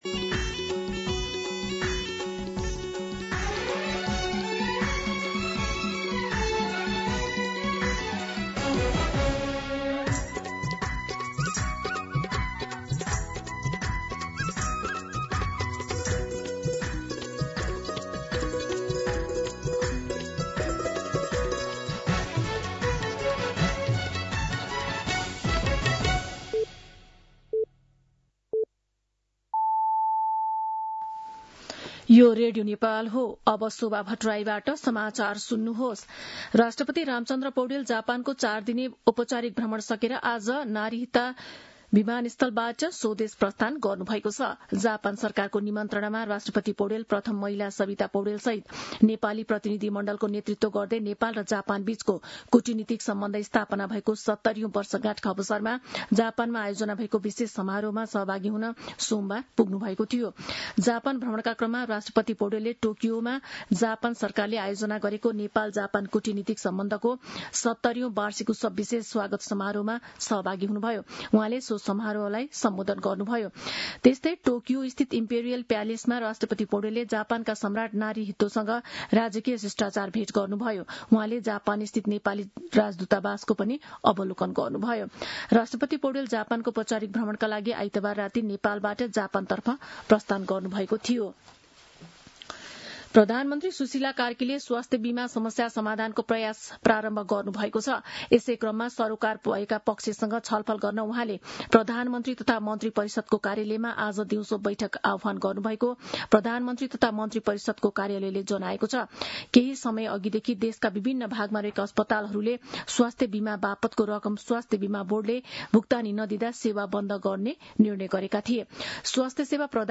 दिउँसो १ बजेको नेपाली समाचार : २१ माघ , २०८२
1-pm-Nepali-News.mp3